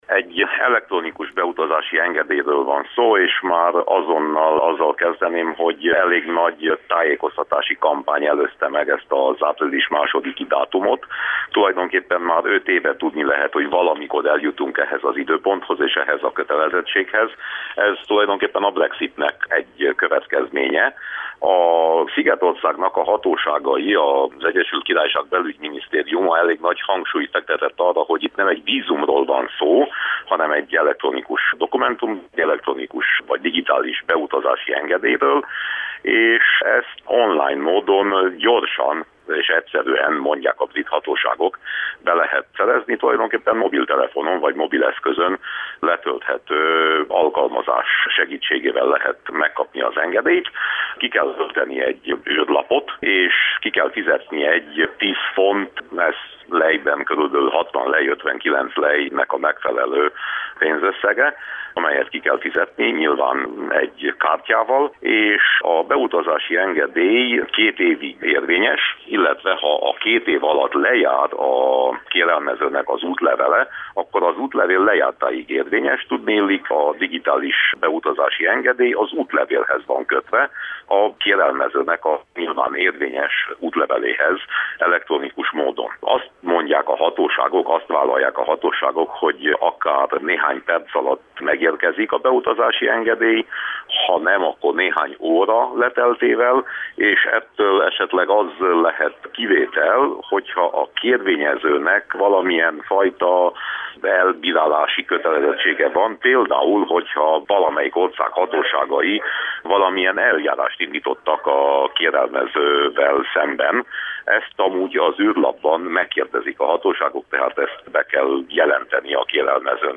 Winkler Gyula EP-képviselővel beszélgettünk az Elektronikus Utazási Engedély rendszeréről.
Az Egyesült Királyság kormánya az illegális bevándorlás visszaszorításáért és a határbiztonság megerősítéséért vezette be az intézkedést, amelynek legfontosabb tudnivalóiról Winkler Gyula Európai Parlamenti képviselő számolt be a Kolozsvári Rádiónak.